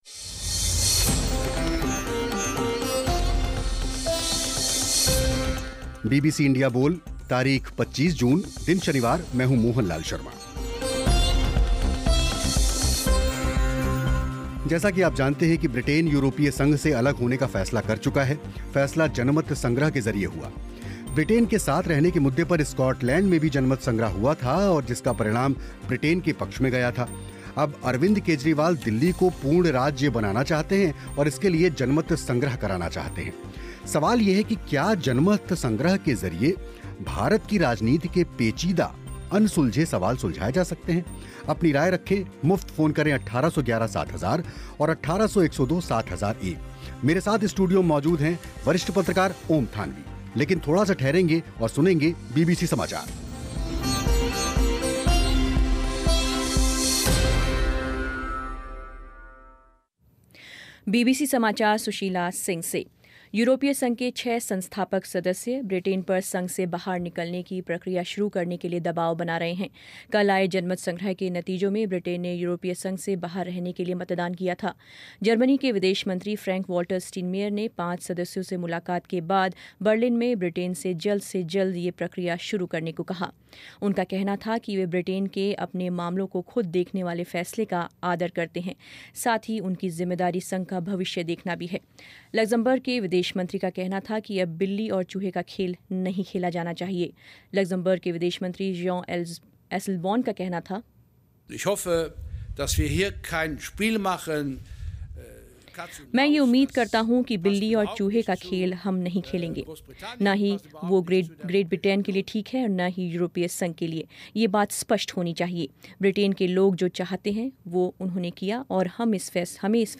अब अरविंद केजरीवाल दिल्ली को पूर्ण राज्य का दर्जा देने के लिए जनमत संग्रह कराना चाहते हैं सवाल है कि क्या जनमत संग्रह के जरिए भारत की राजनीति के पेचीदा सवाल सुलझाए जा सकते हैं. स्टूडियो में थे वरिष्ठ पत्रकार ओम थानवी और फोन पर थे आम आदमी पार्टी के प्रवक्ता आशुतोष.